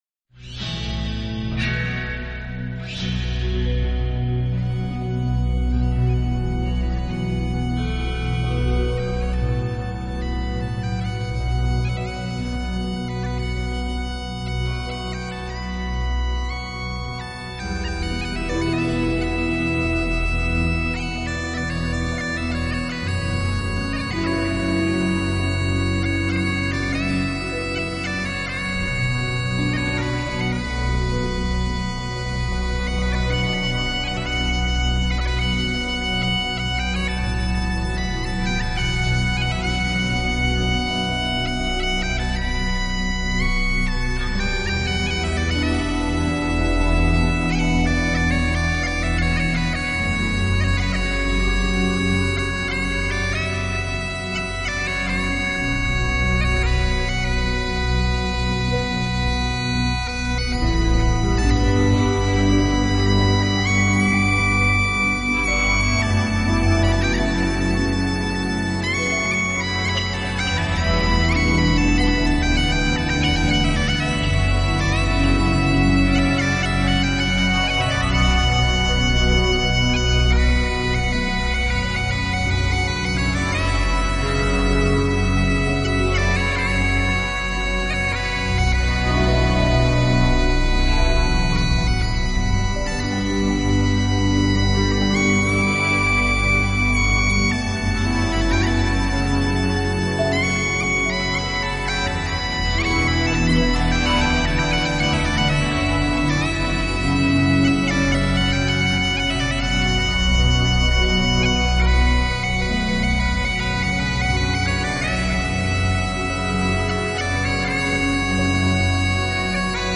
之处的，秉承了非常Celtic的传统，多乐器的配合，以及对风笛本身音色的改良，